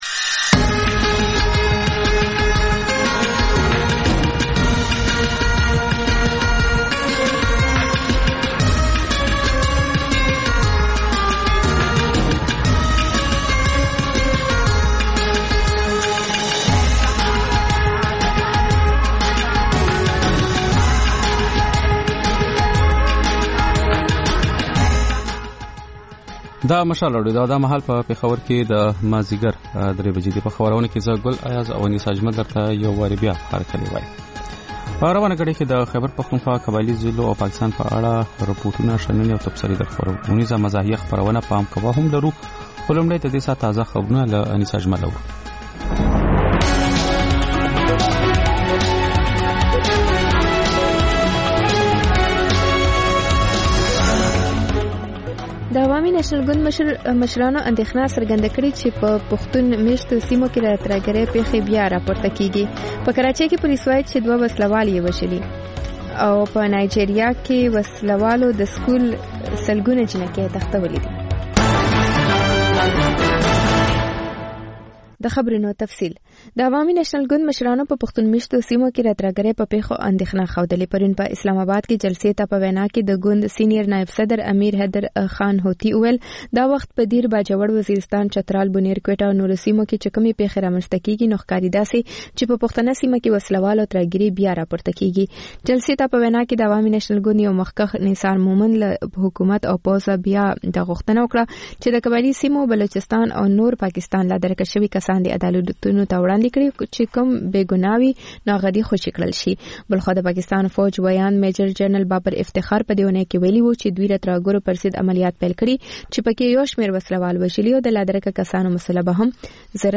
د مشال راډیو درېیمه یو ساعته ماسپښینۍ خپرونه. تر خبرونو وروسته، رپورټونه او شننې خپرېږي. ورسره اوونیزه خپرونه/خپرونې هم خپرېږي.